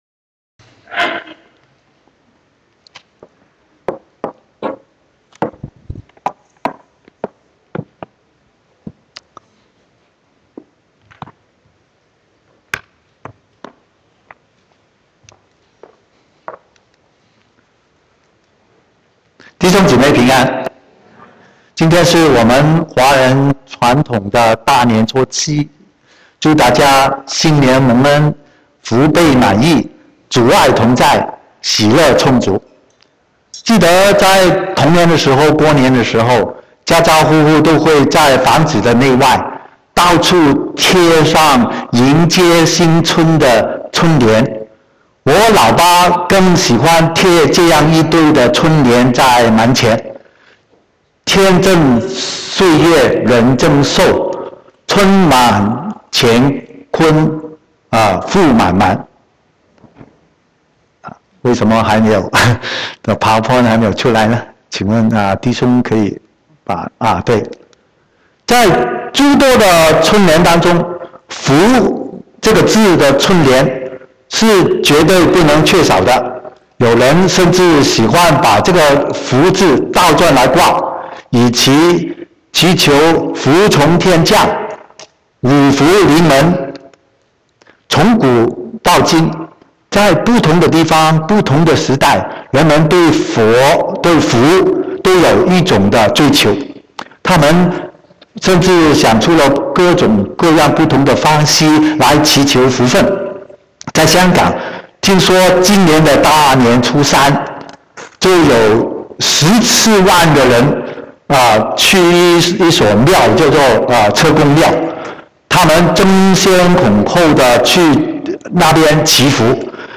14/2/2016國語堂講道